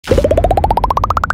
Лопаются множество пузырей